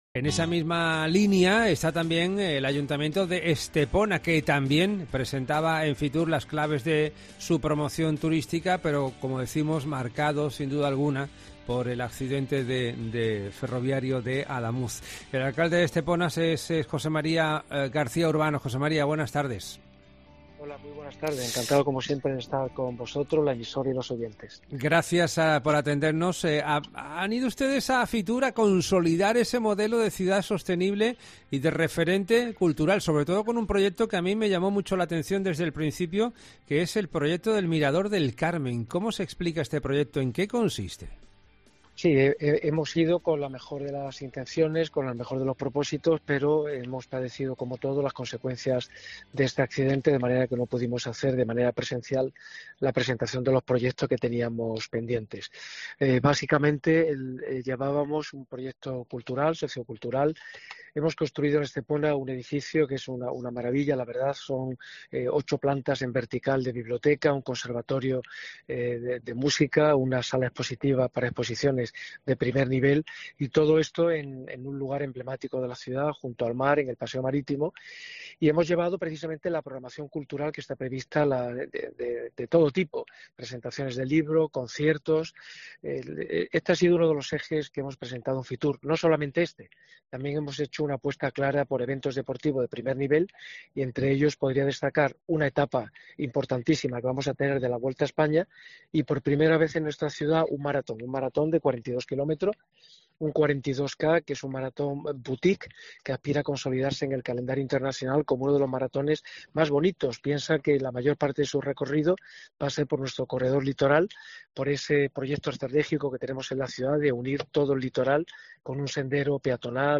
Entrevista a José María García Urbano alcalde de Estepona